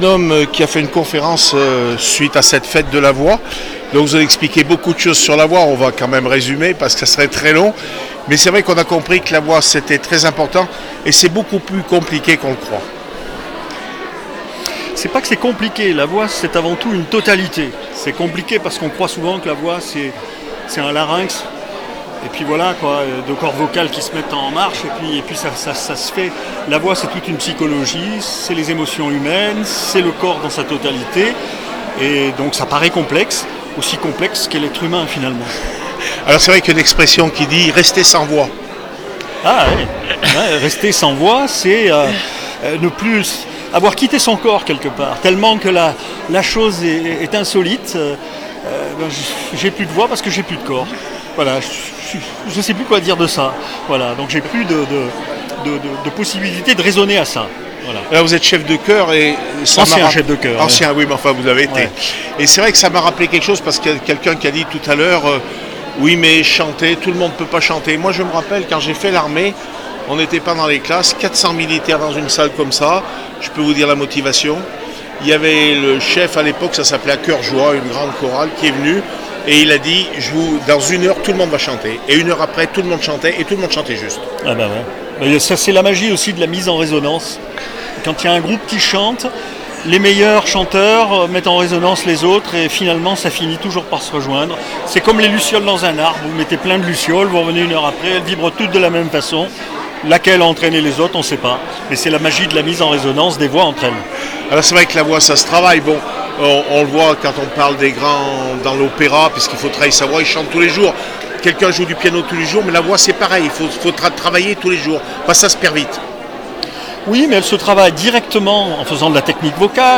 CONFERENCE SUR LA VOIX FETE DE LA VOIX CRAPONNE SUR ARZON 28/05/16